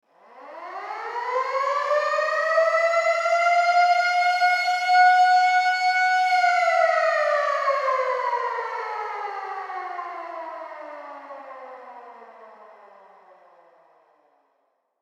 Air Raid Siren Sound Effect